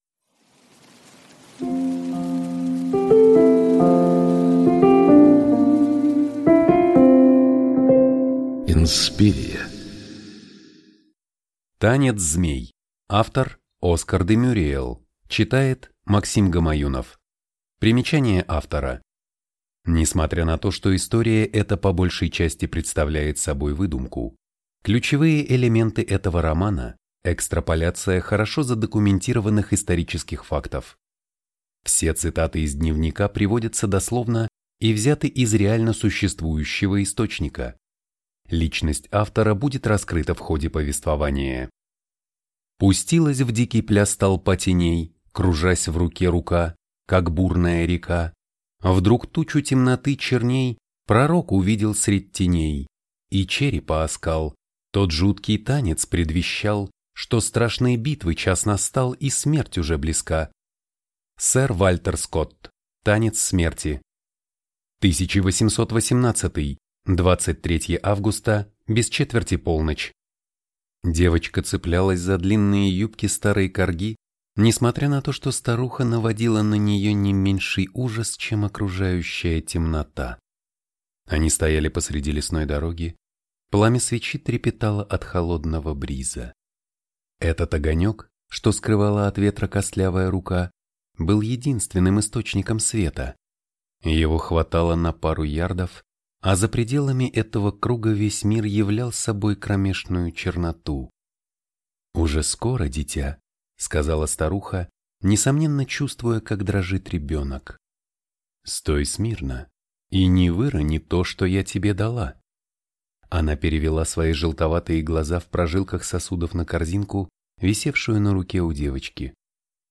Аудиокнига Танец змей | Библиотека аудиокниг
Прослушать и бесплатно скачать фрагмент аудиокниги